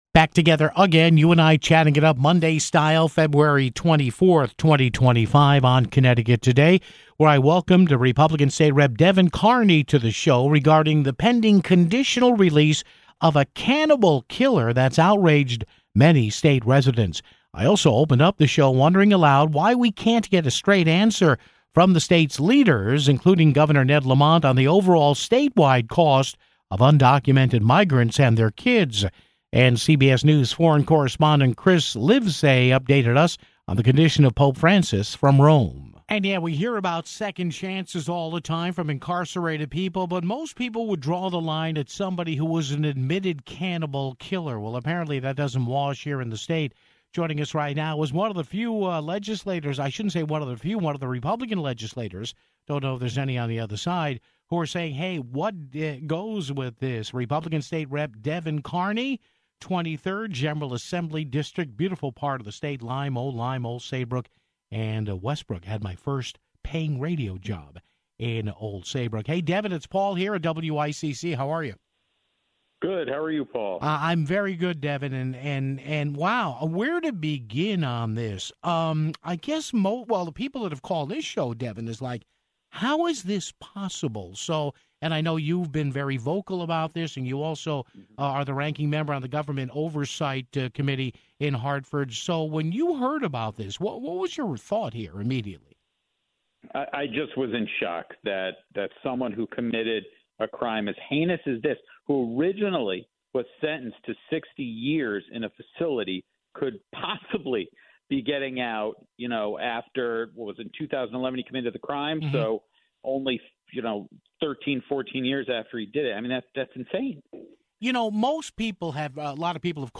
spoke with GOP State Rep. Devin Carney on Monday's "Connecticut Today" regarding the pending conditional release of a cannibal killer that's outraged many state residents (00:36).